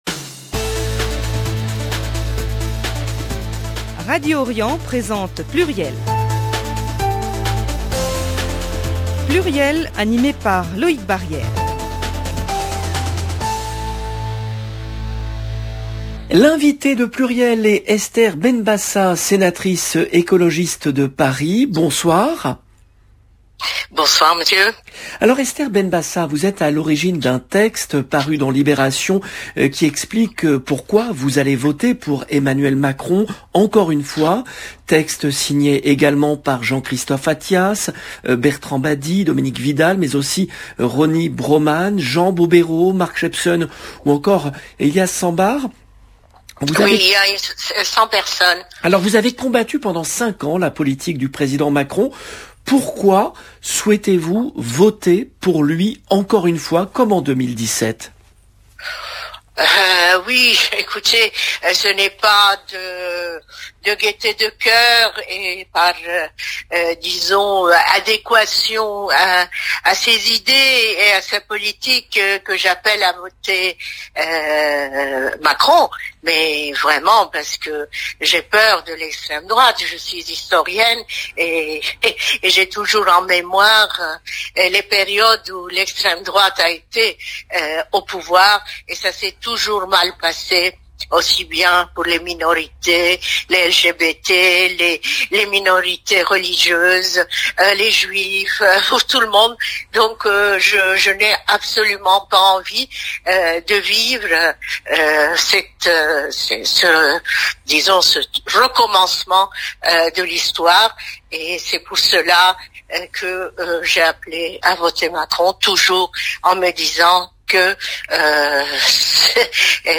Esther Benbassa, sénatrice écologiste de Paris